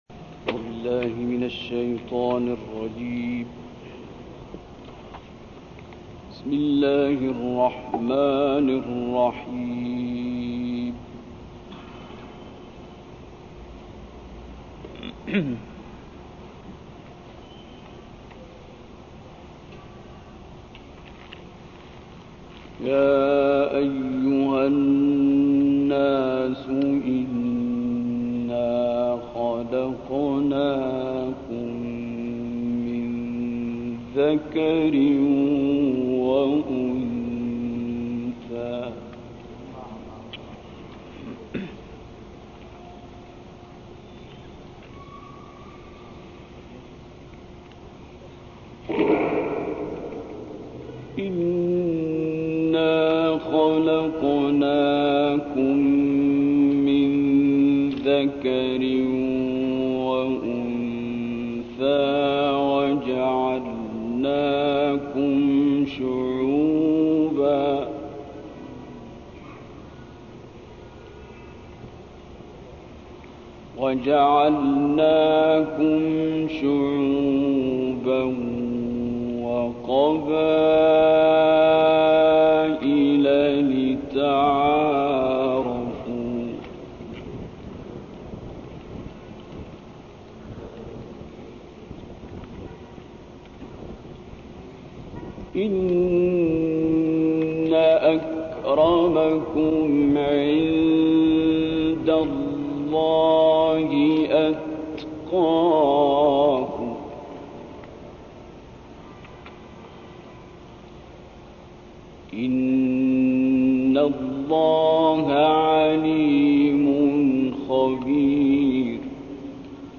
تلاوت «عبدالباسط» در مسجد الفردوس عراق
گروه فعالیت‌های قرآنی: تلاوت آیاتی از سور حجرات و ق با صوت عبدالباسط محمد عبدالصمد که در مسجد الفردوس عراق اجرا شده است، می‌شنوید.
این تلاوت در مجلس عزای آل الخضیری در مسجد الفردوس عراق اجرا شده و شیخ احمد الرزيقي همراه عبدالباسط در این سفر بوده است، فایل صوتی تلاوت در کانال تلگرامی این قاری برجسته(جامع تراث الذهب الخالص) منتشر شده است.